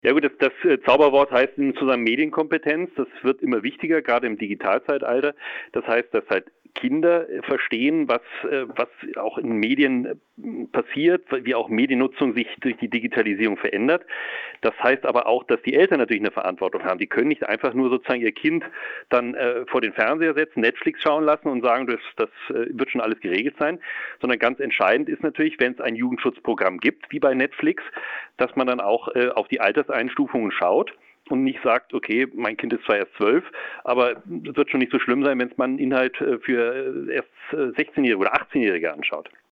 - Interview mit BLM-Präsidenten Dr- Thorsten Schmiege - PRIMATON